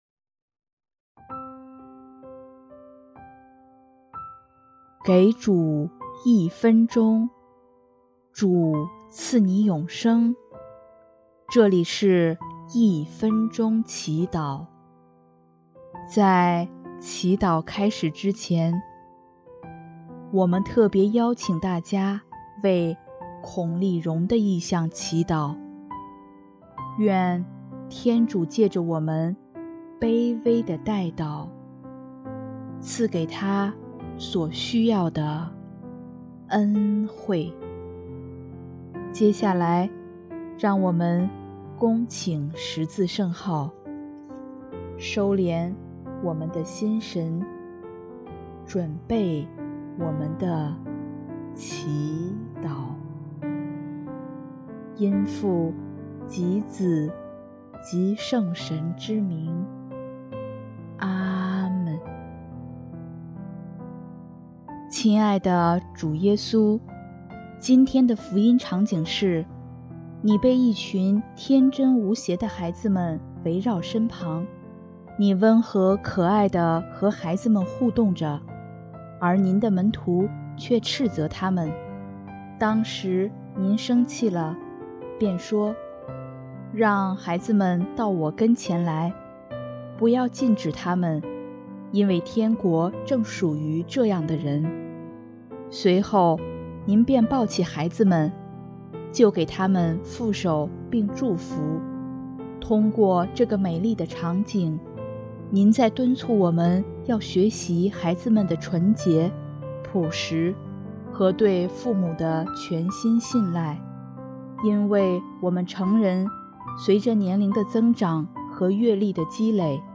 音乐：第四届华语圣歌大赛参赛歌曲《上主我要歌颂祢》